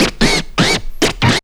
5404L SCRACH.wav